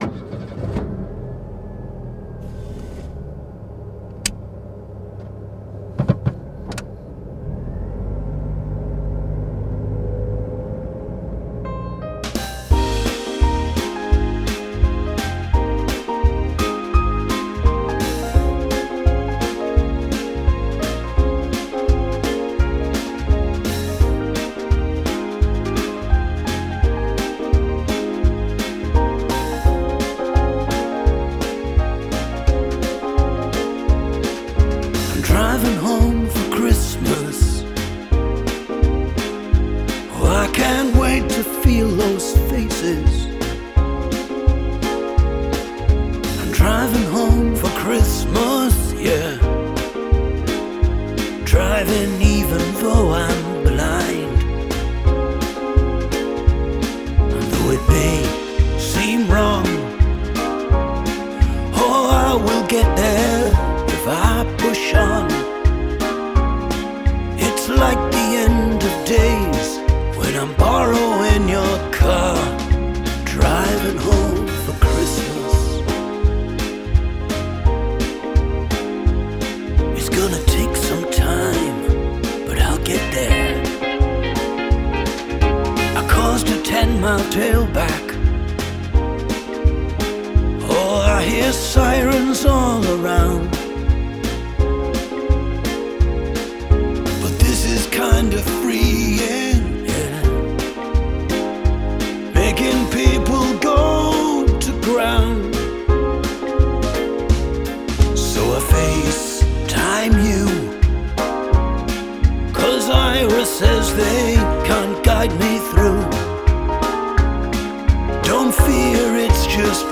drums
piano/keys/strings
guitar